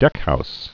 (dĕkhous)